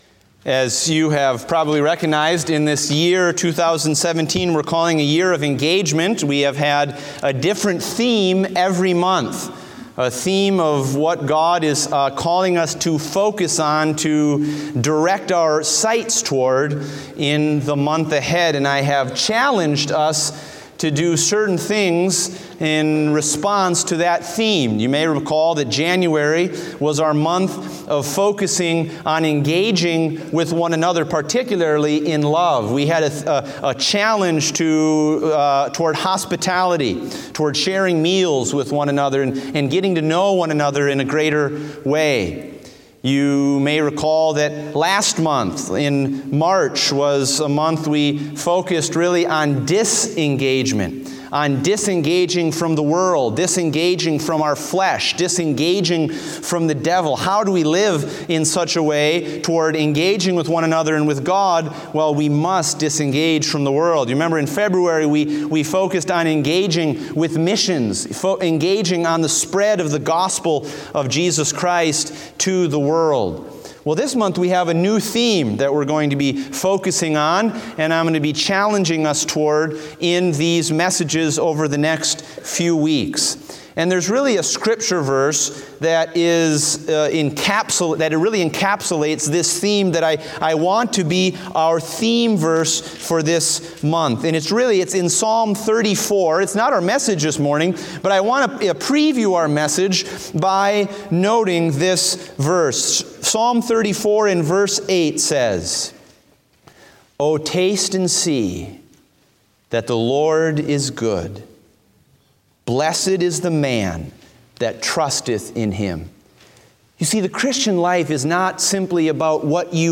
Date: April 2, 2017 (Morning Service)